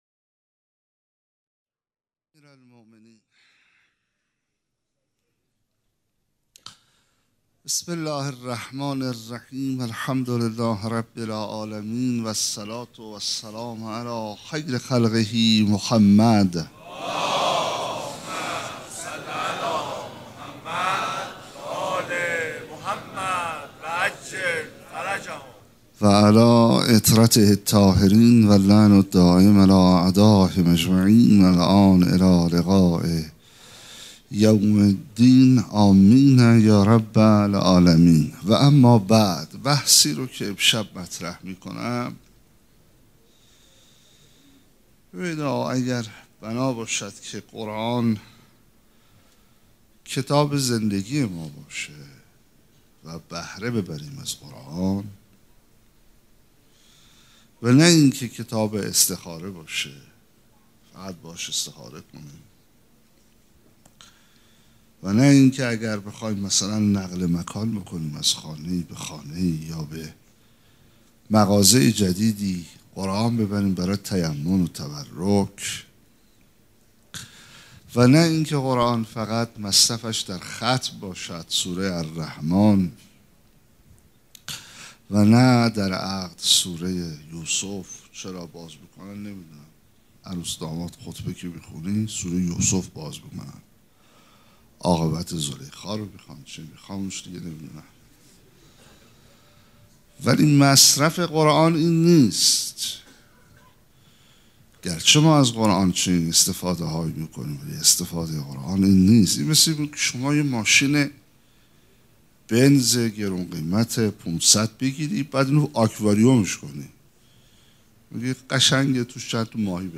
سخنرانی
سخنرانی شب دوم فاطمیه ۹۸
sokhanrani.mp3